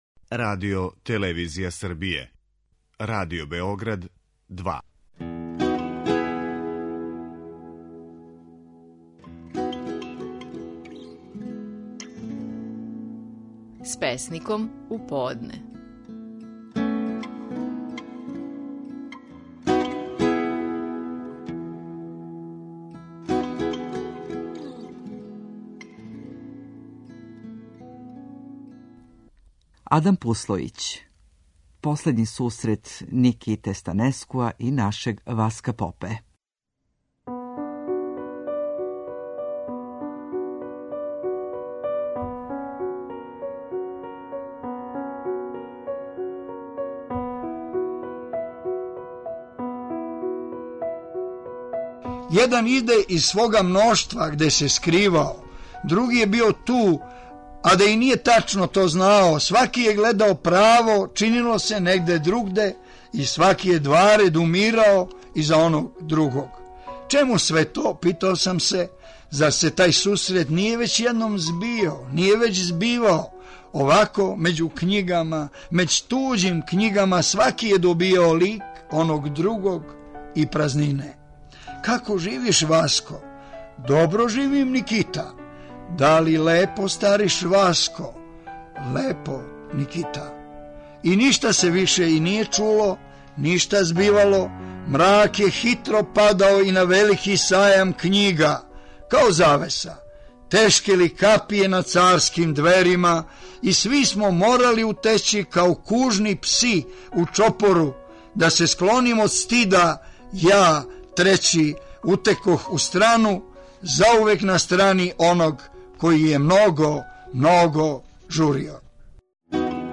Наши најпознатији песници говоре своје стихове